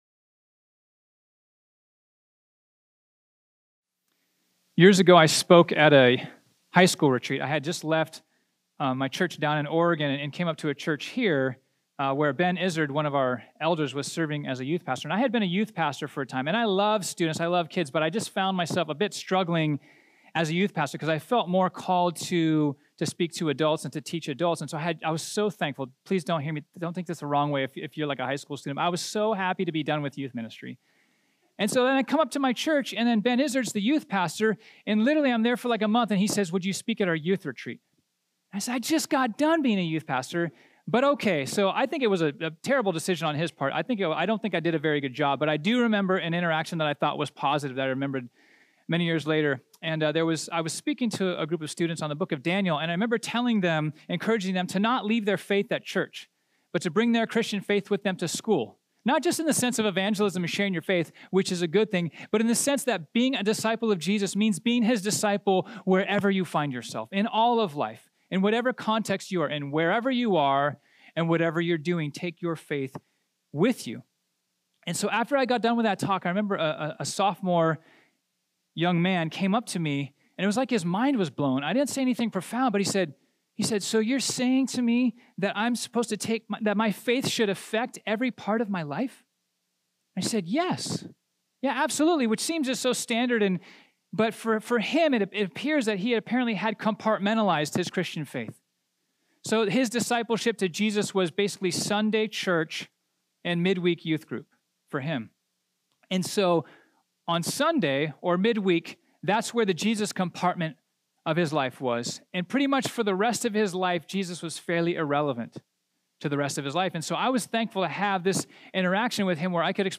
This sermon was originally preached on Sunday, November 18, 2018.